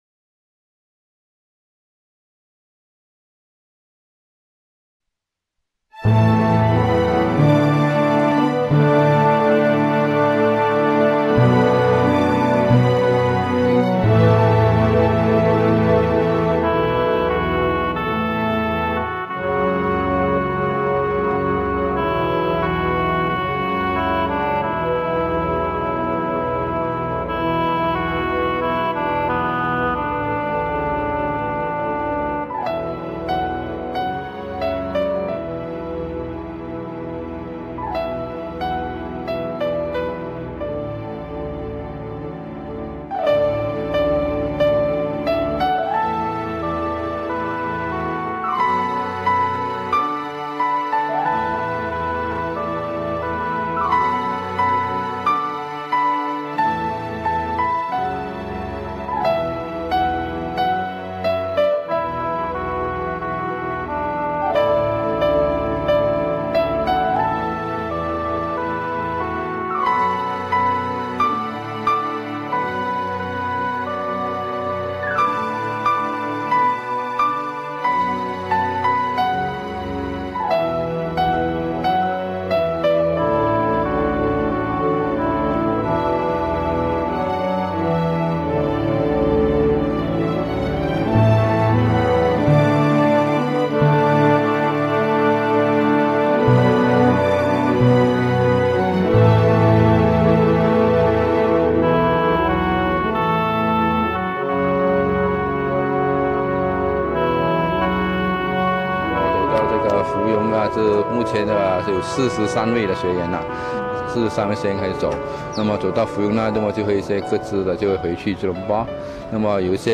Hui_Gui_lu-Feb (music) 256kB.rm